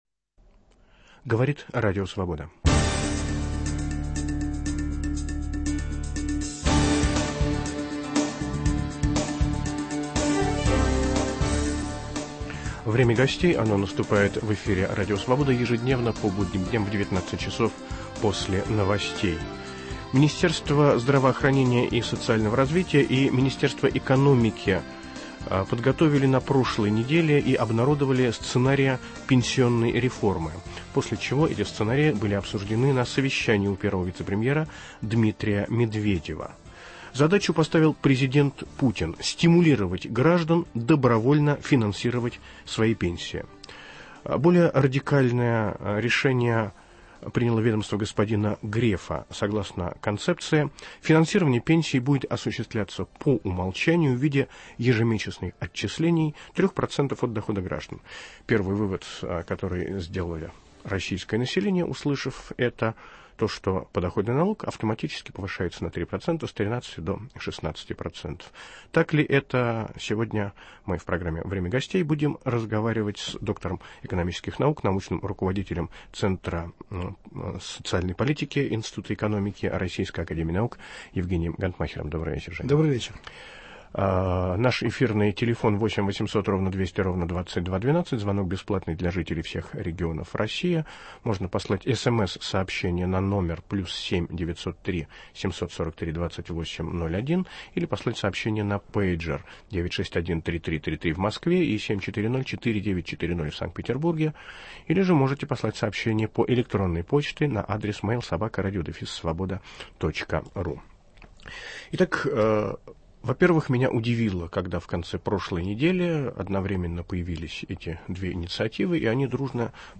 В программу приглашен доктор экономических наук, руководитель центра социальных исследований и инноваций Евгений Гонтмахер.